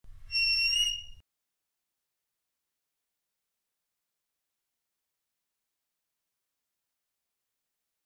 Teeth on Reed
this time as an isolated portamento scream
for solo clarinet